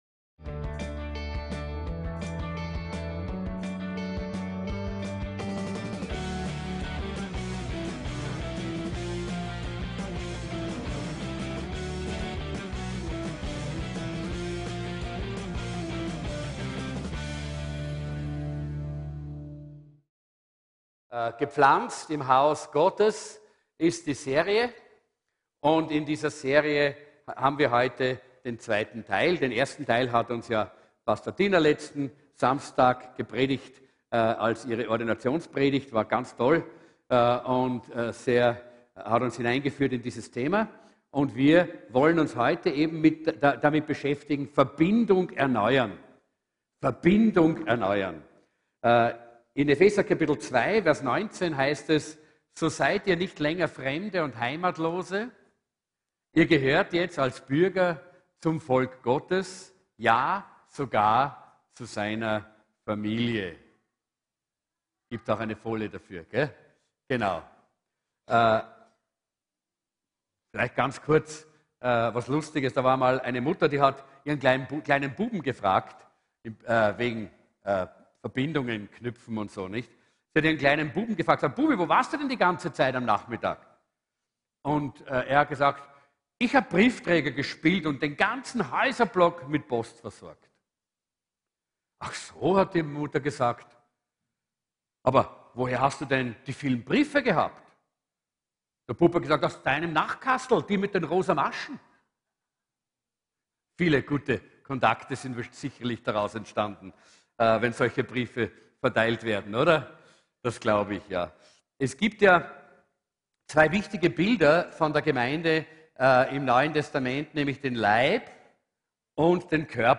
VERBINDUNG ERNEUERN-GEPFLANZT IM HAUS GOTTES ~ VCC JesusZentrum Gottesdienste (audio) Podcast